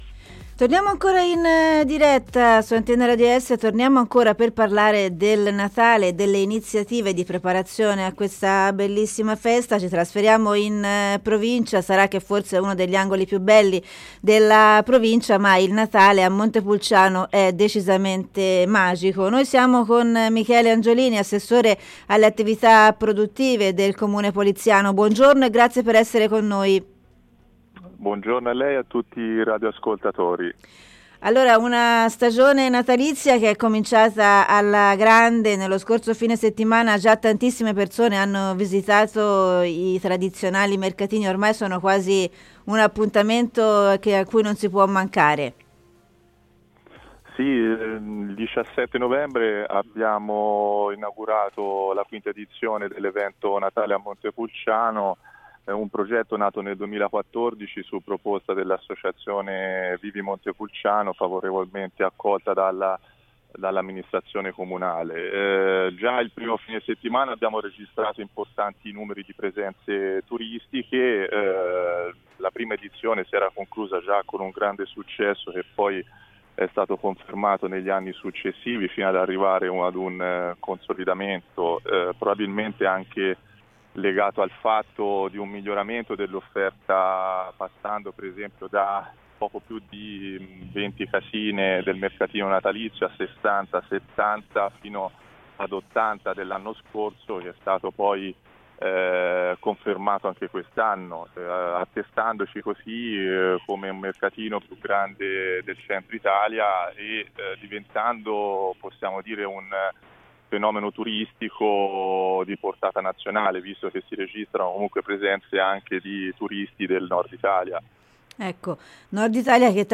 Ne abbiamo parlato con l’assessore alle attività produttive del comune poliziano Michele Angiolini